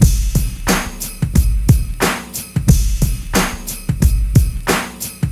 • 90 BPM G Gangsta Beat.wav
Gangsta rap drum loop if you need that old school gangsta vibe - bass drum tuned to G
90_BPM_G_Gangsta_Beat_L4X.wav